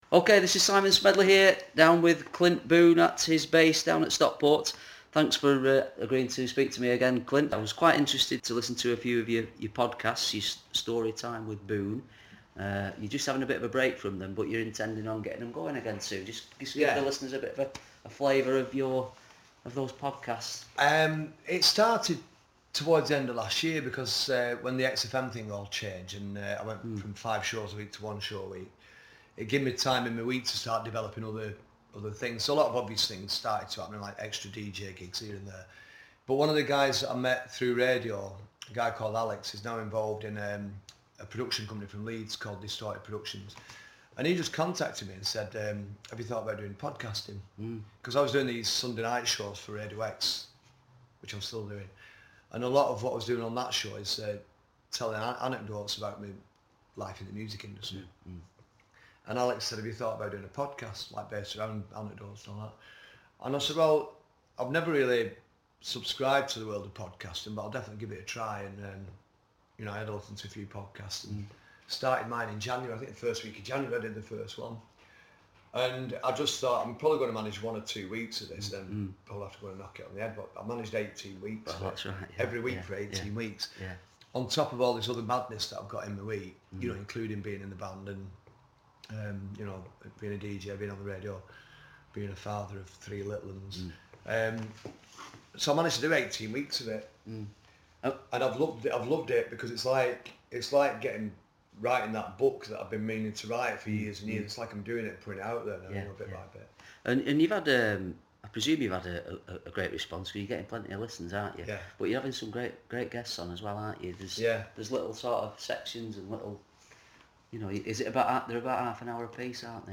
Clint Boon in conversation